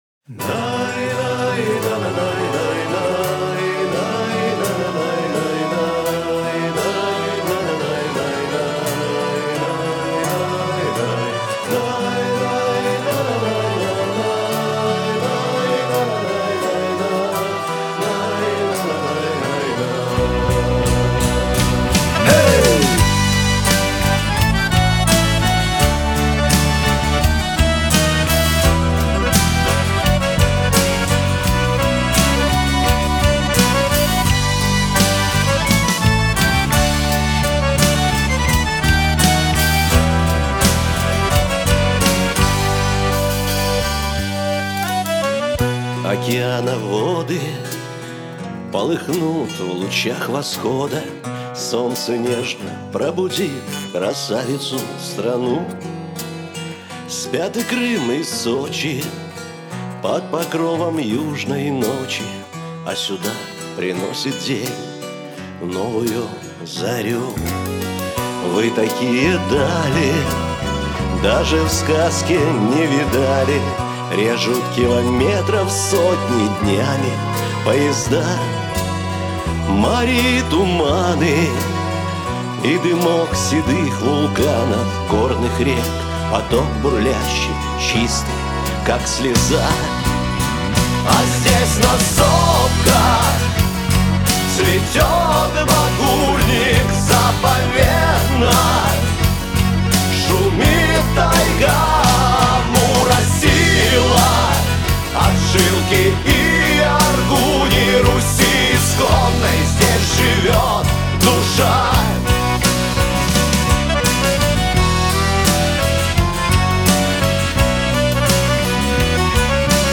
Лирика
ансамбль